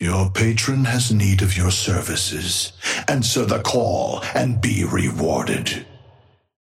Amber Hand voice line - Your Patron has need of your services.
Patron_male_ally_wrecker_start_05.mp3